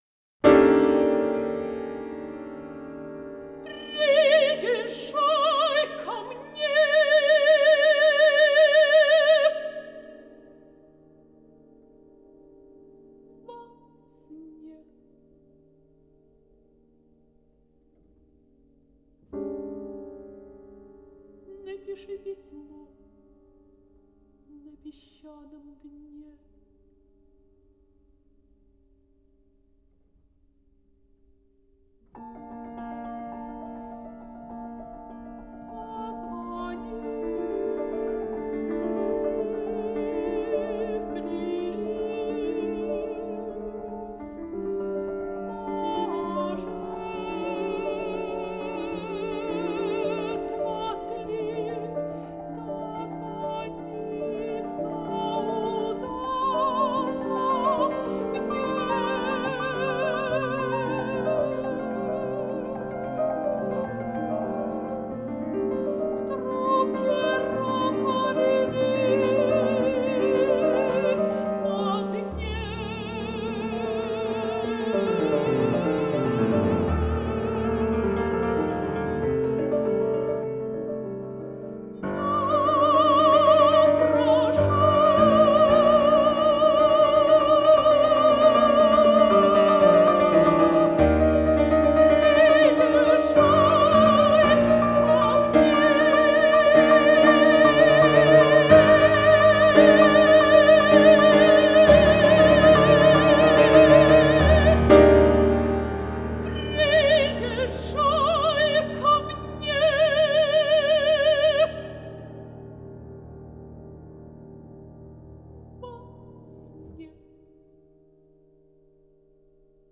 Вокальный цикл
Жанр: Классика/вокал